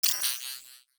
Robotic Game Notification 3.wav